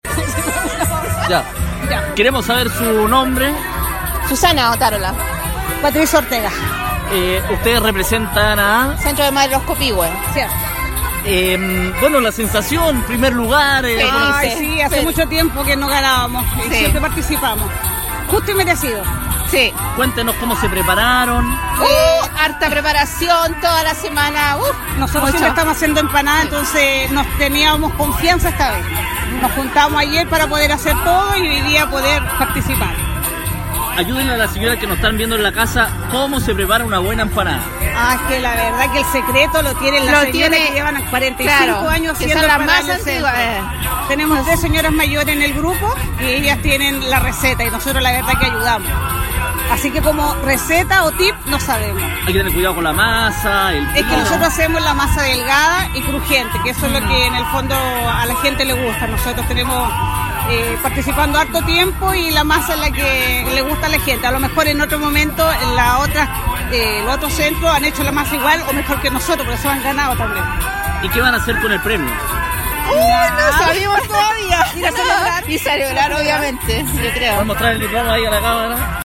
Para comenzar el mes de la patria como corresponde, en la Plaza Patricio Lynch de Concón se realizó el “XX Concurso de Empanadas 2018”, donde12 organizaciones comunitarias compitieron para ser la mejor preparación de esta tradicional comida chilena.
GANADORAS-X-EMPANADAS-2-.mp3